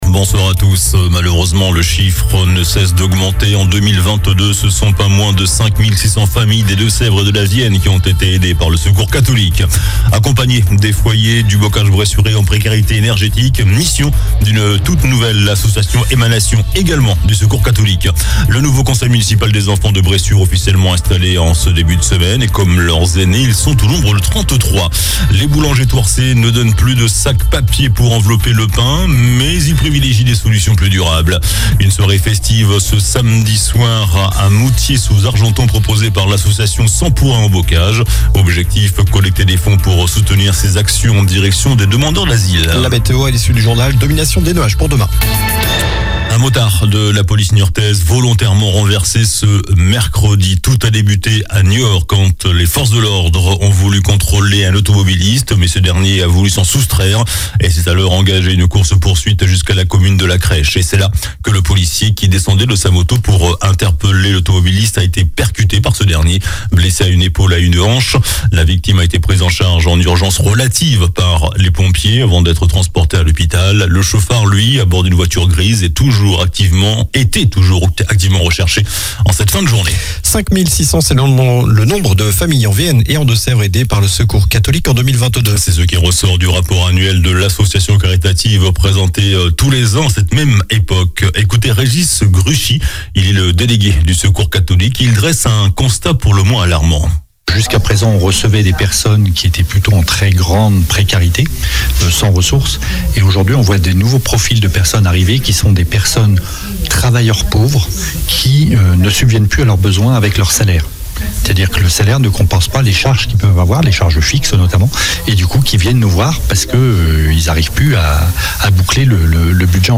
JOURNAL DU MERCREDI 22 NOVEMBRE ( SOIR )